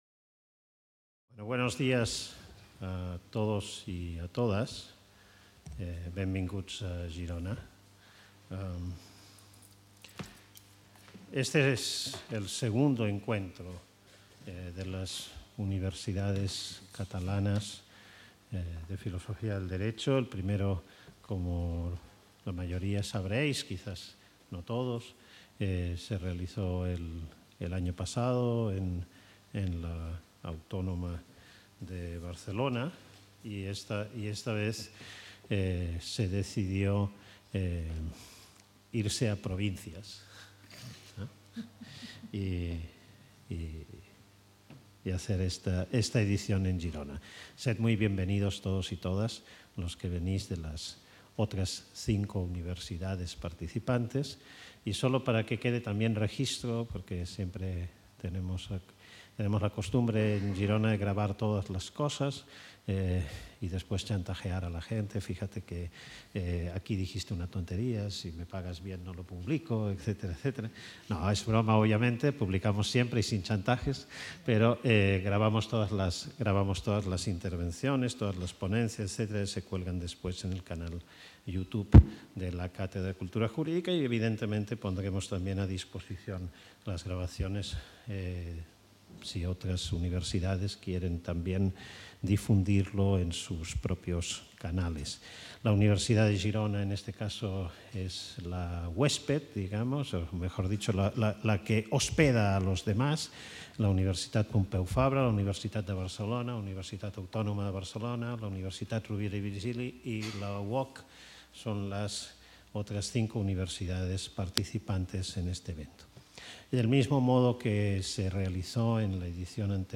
Benvinguda i presentació del seminari